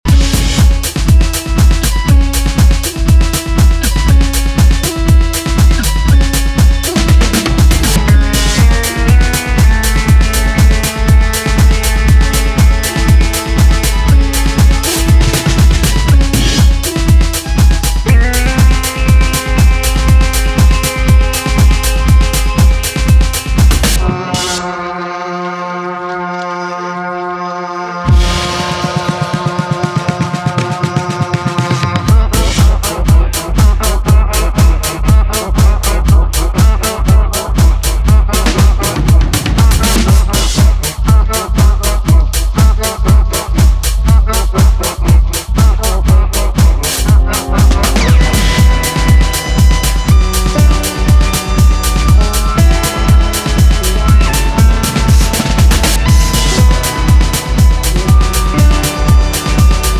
ドラムの躍動が深い時間の狂気を呼び起こす
長いディスコ/ハウス史への眼差しを感じさせる楽曲を展開